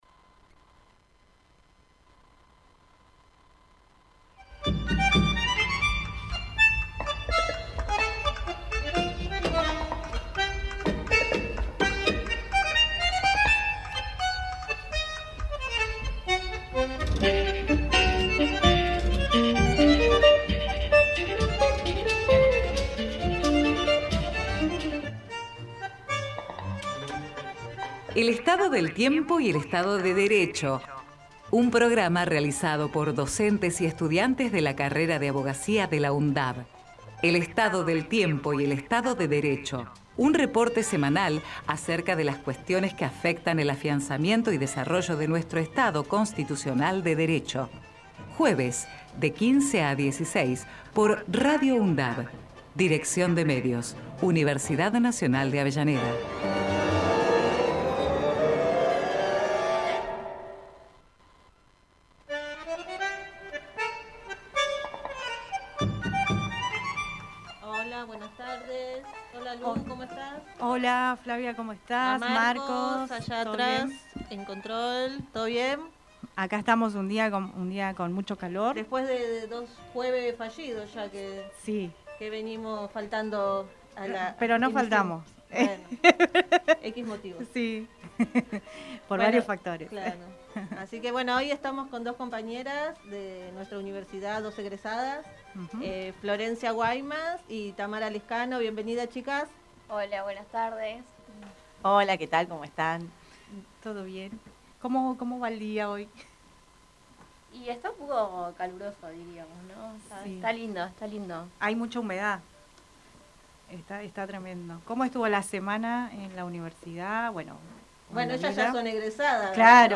El Estado del Tiempo y el Estado de Derecho Texto de la nota: El Estado del Tiempo y el Estado de Derecho es un programa realizado por estudiantes y docentes de la carrera de Abogacía de la Universidad Nacional de Avellaneda, fue emitido por Radio UNDAV desde el año 2016 todos los jueves de 15 a 16hs.